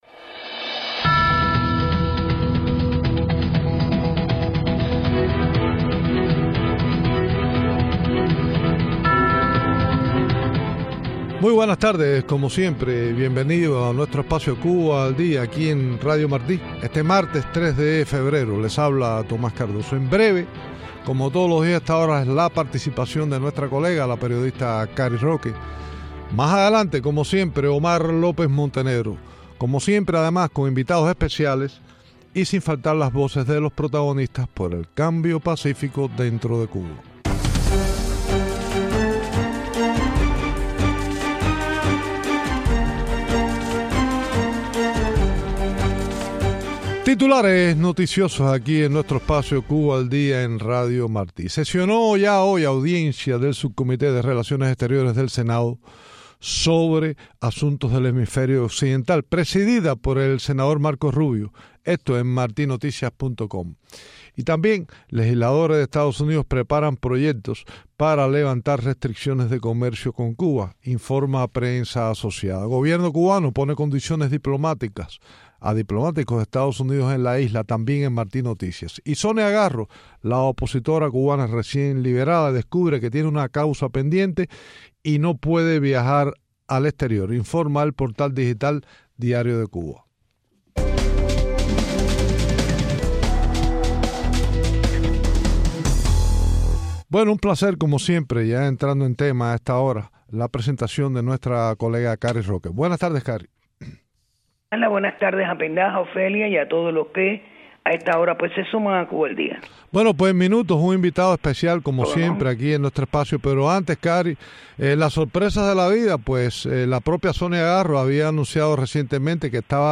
Entrevistas con Lincoln Diaz Balart y Jose Daniel Ferrer en Cuba.